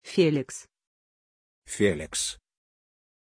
Pronuncia di Feliks
pronunciation-feliks-ru.mp3